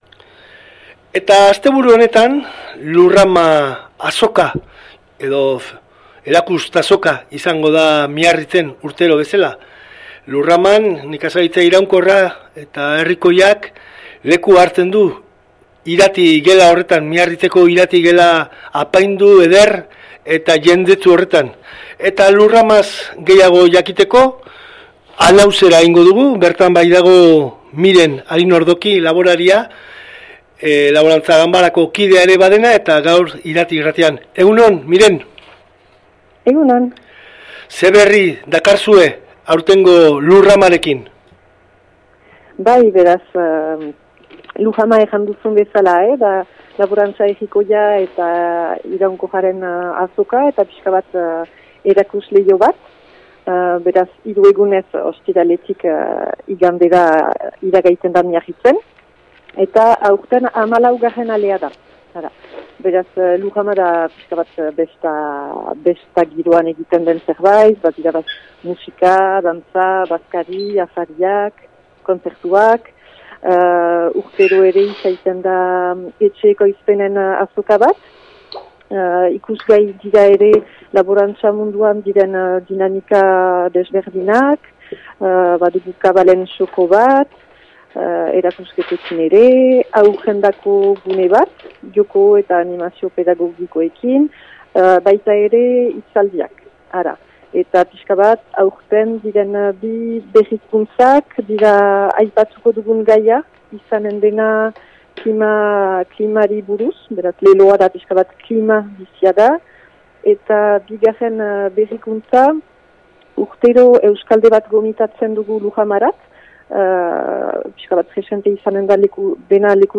laborariarekin mintzatu gara Lurrama laborantza iraunkorraren inguruko azokari buruz. Euskal Herriko Laborantza Ganbarak antolatzen du Lurrama Miarritzen eta Nafarroa izanen da herrialde gonbidatua aurtengoan.